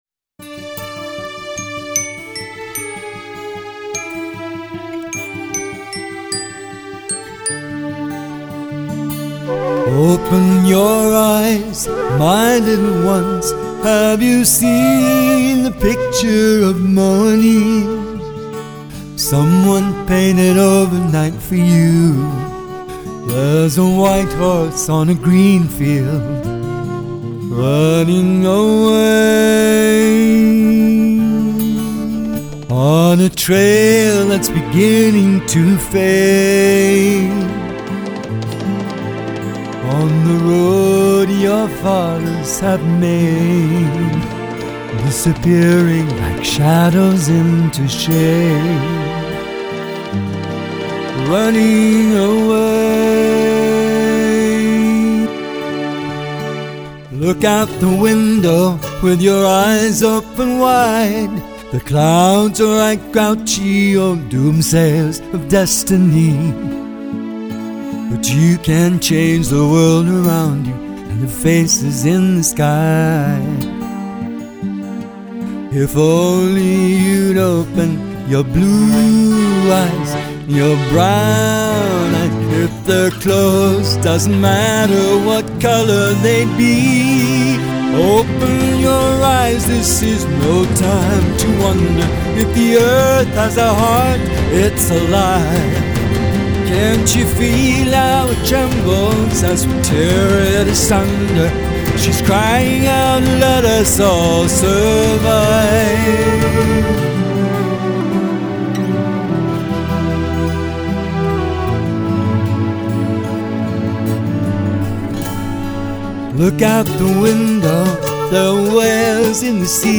I work with a TASCAM DP-24SD, without any DAW support, so my mixes are always one-time events, hands-on-try-to-get-a-nice-balance moments of great excitement and expectation I guess the genre of this song could be thought of as "pop rock".